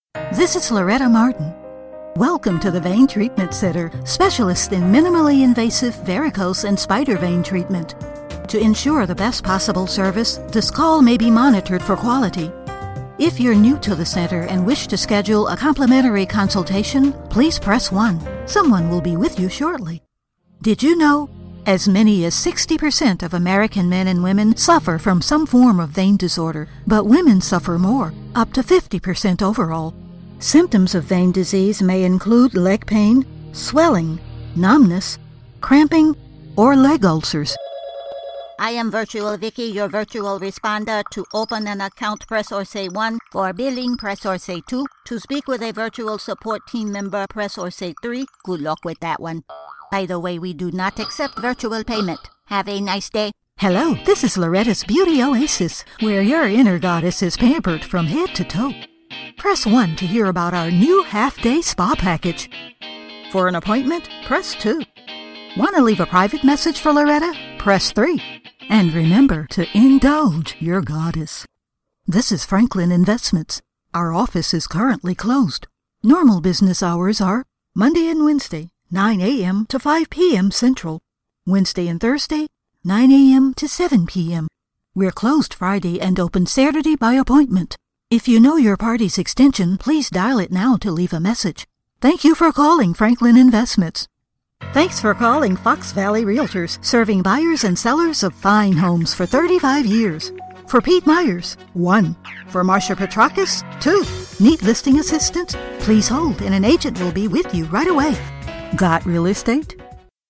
Medium to upper register. Middle America, Southern "Dixie," African American (urban, not "street")
Sprechprobe: Industrie (Muttersprache):
Natural warmth, conversational, quirky, mature, corporate, cosmopolitan or folksy